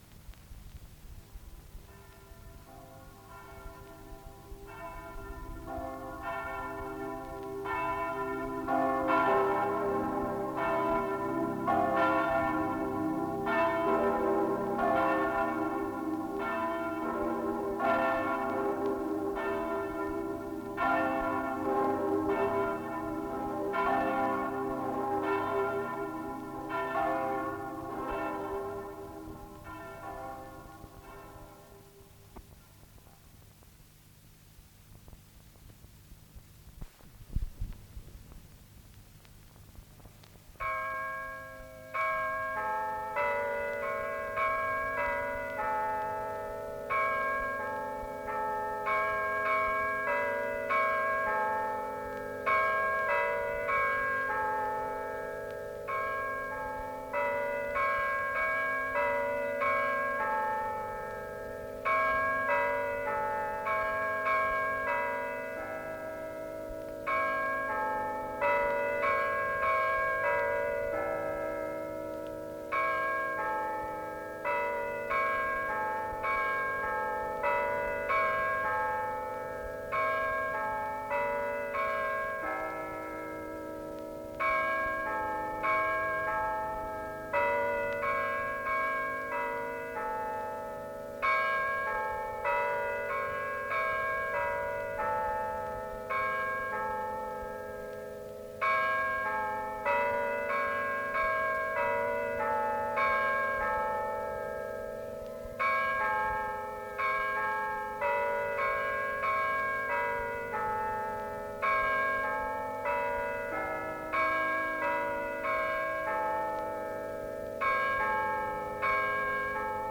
Sonneries de cloches
Lieu : Mas-Cabardès
Genre : paysage sonore Instrument de musique : cloche d'église